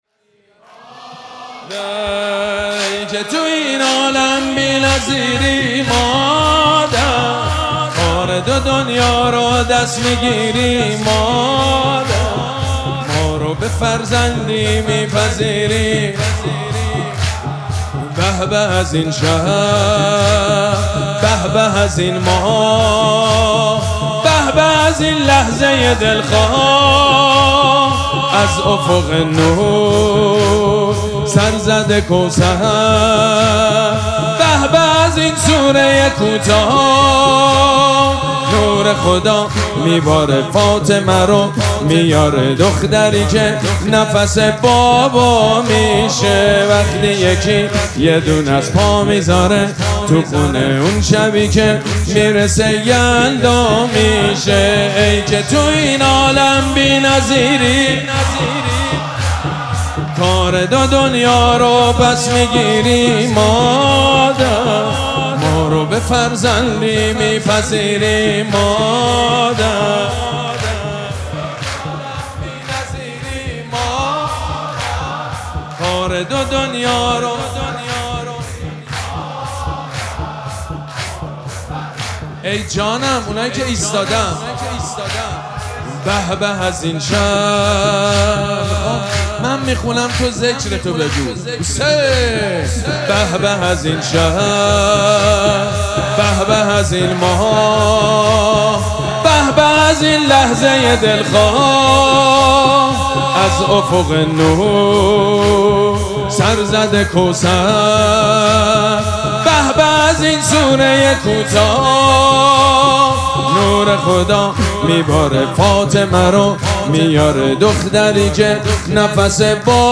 مراسم جشن ولادت حضرت زهرا سلام الله علیها
سرود
حاج سید مجید بنی فاطمه